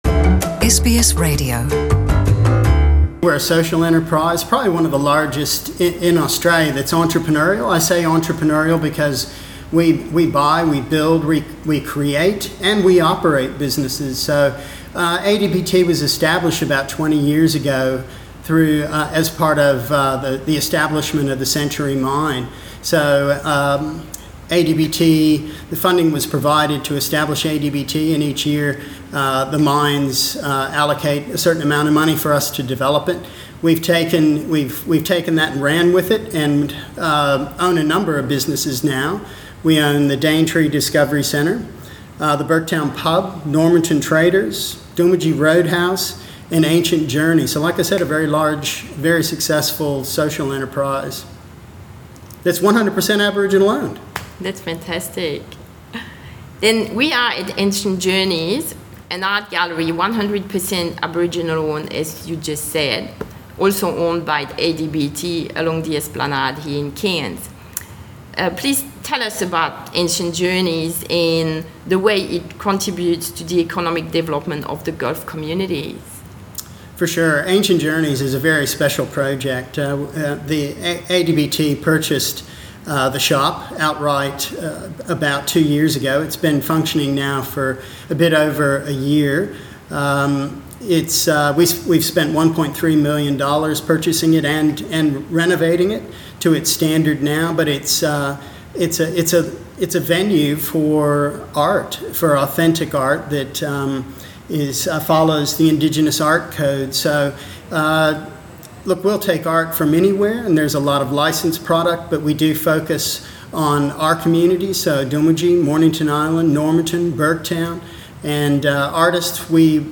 SBS NITV Radio